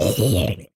Minecraft Version Minecraft Version snapshot Latest Release | Latest Snapshot snapshot / assets / minecraft / sounds / mob / zombie / death.ogg Compare With Compare With Latest Release | Latest Snapshot
death.ogg